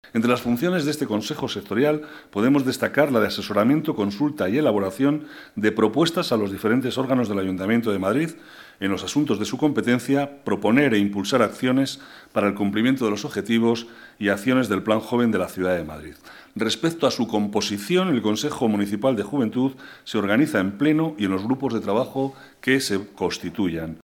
Nueva ventana:Declaraciones vicealcalde, Manuel Cobo: aprobado Reglamento del Consejo Sectorial de la Juventud